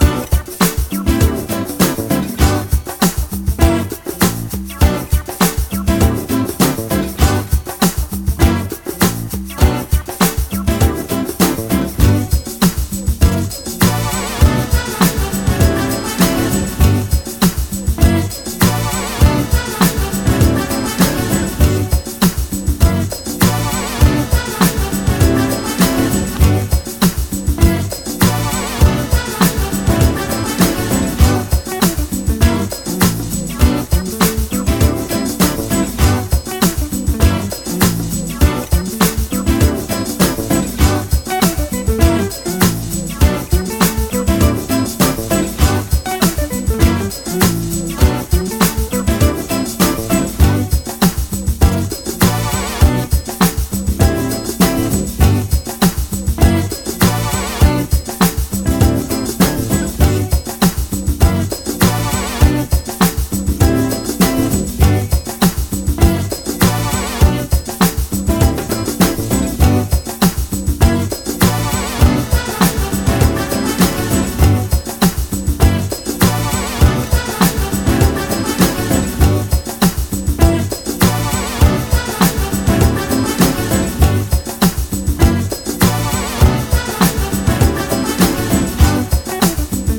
ジャンル(スタイル) DEEP HOUSE / NU DISCO